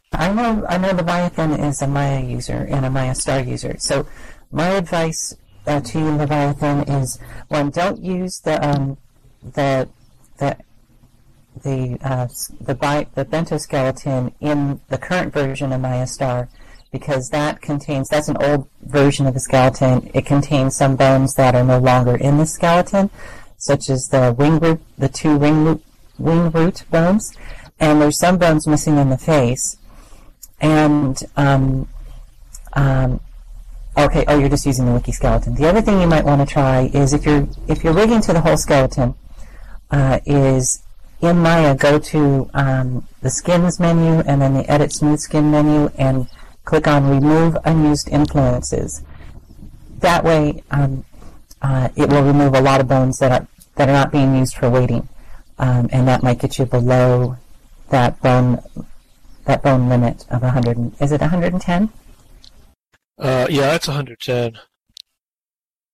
The following notes and audio were taken from the weekly Bento User Group meeting, held on Thursday, June 16th at 13:00 SLT at the the Hippotropolis Campfire Circle .